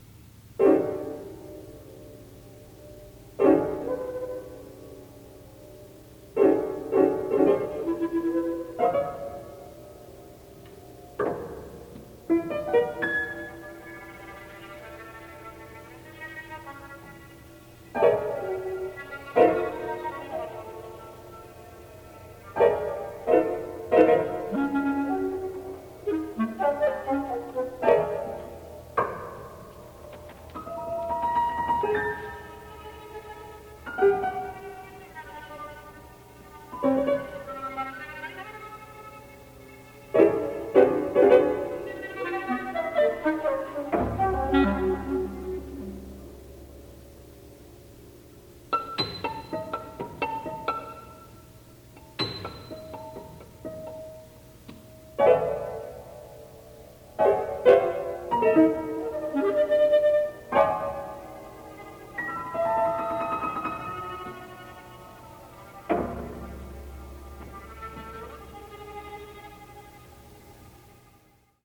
And now let's hear the third movement of Crumb's Eleven Echoes of Autumn from 1965, which likewise uses a group of serialist gestures repeated over and over in in a kind of harmonic stasis. These clusters in the piano never move from their original register, and the violin continues musing on the same few notes. [*] This is from a noncommercial recording made in 1974 in which I am playing the piano along with a couple of my fellow students at Oberlin Conservatory.